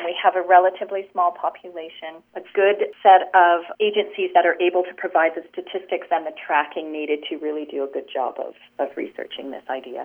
Councillor Valerie Warmington says Nelson would be a great fit.